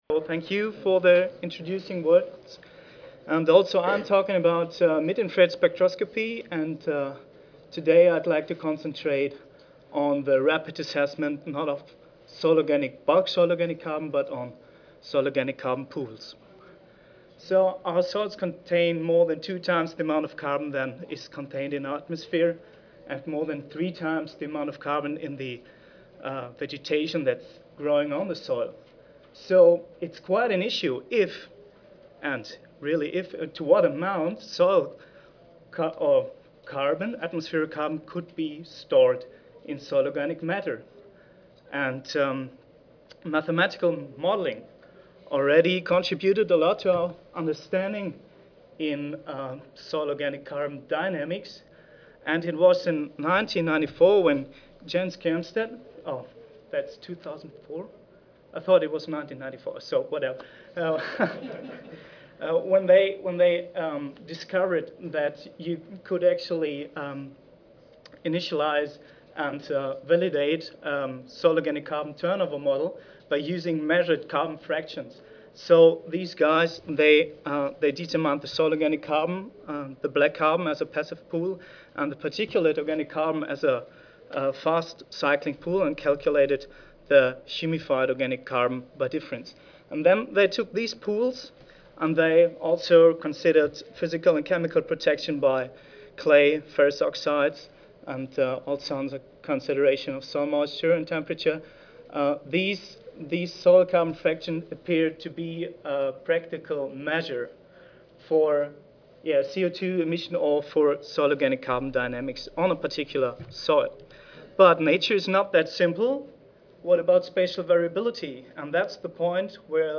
GERMANY Audio File Recorded presentation Modeling global C cycles requires in-depth knowledge about small scale C stocks and turnover processes.